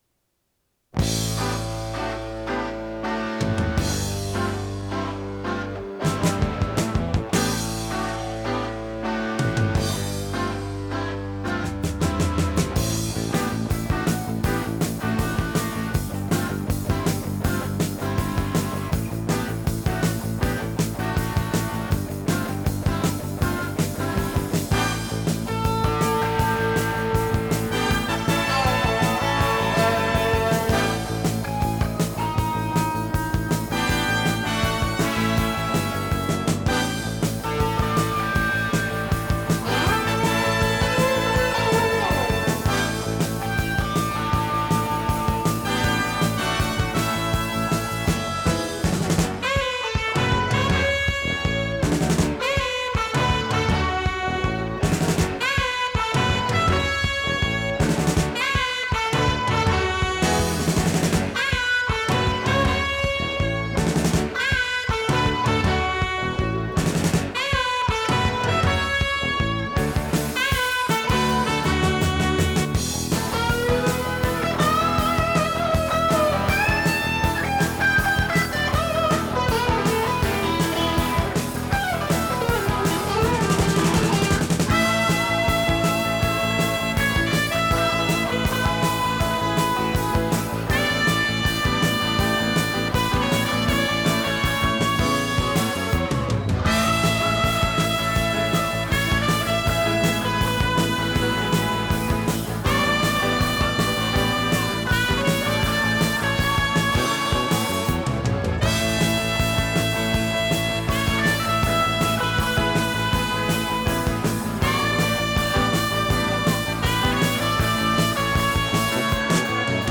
○Pioneerのデッキの中ではやや珍しい堅実で素直な音質
テープ：RTM
ノイズリダクションOFF
【フュージョン・ロック】96kHz-24bit 容量52.7MB